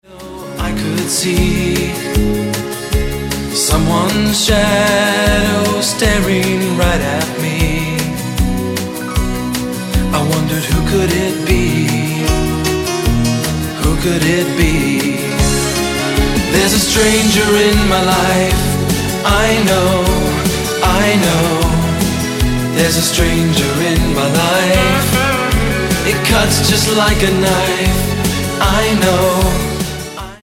The Disco Star of the 80s!